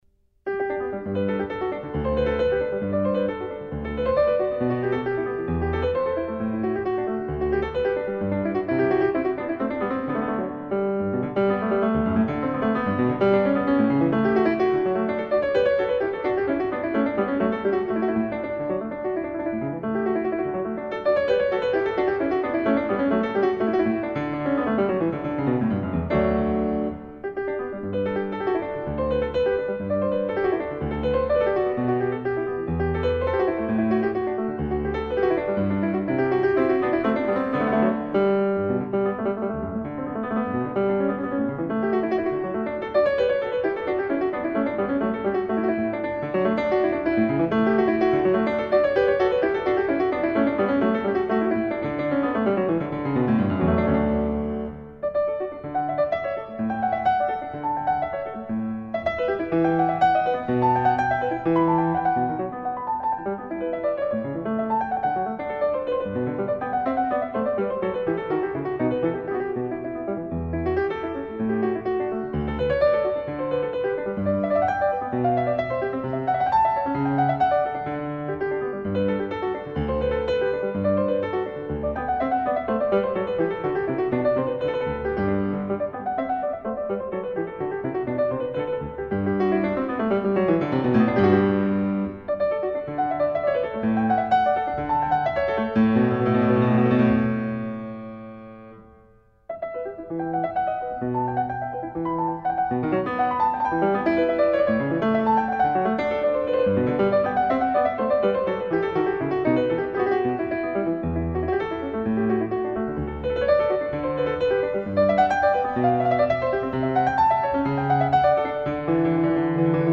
En complément vient la suite en sol majeur,  avec son crépitant allegro et sa gigue endiablée.
Son jeu possède toutes les qualités, simple et clair mais jamais terne, débordant d’une réjouissante et communicative énergie sans jamais sombrer dans la moindre hystérie.